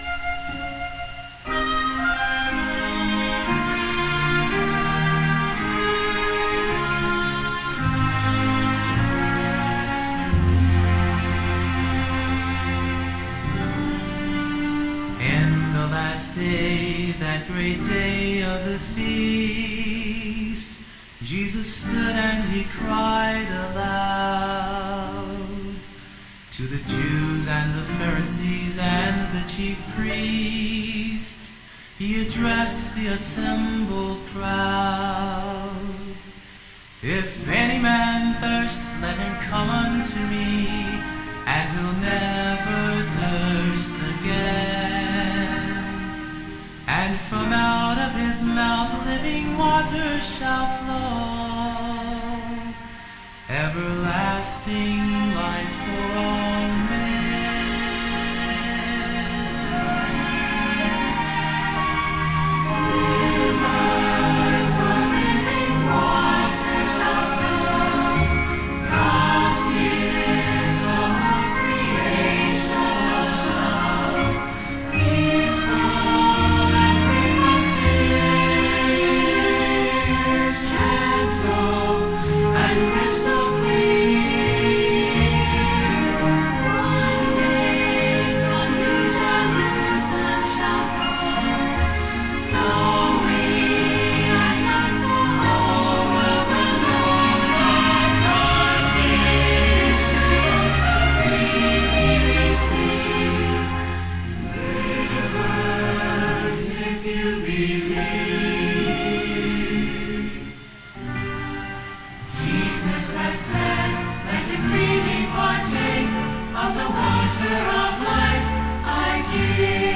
This sermon was given at the Canmore, Alberta 2014 Feast site.